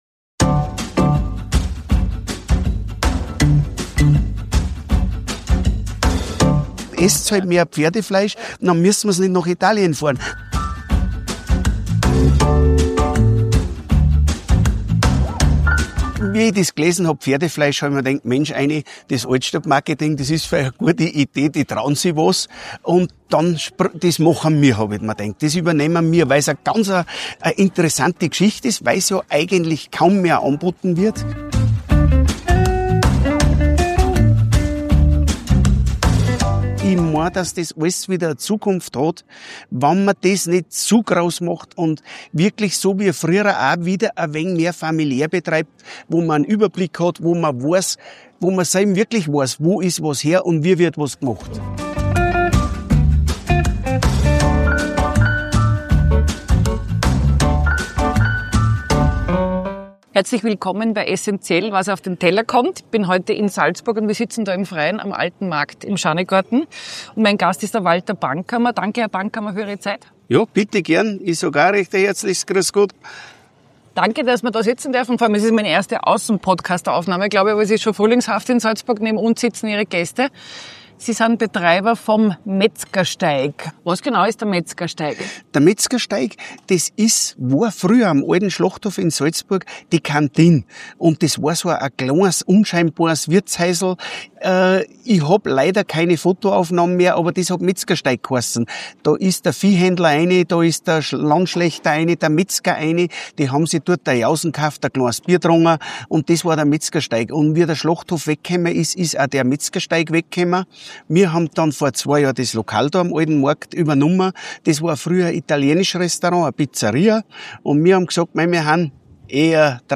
Wie groß war die Aufregung, wer kam, um zu kosten und überlegt er, selbst künftig auch Pferde zu schlachten? Ein Gespräch im Schanigarten des Gasthaus Metzgersteig am Alten Markt in Salzburg.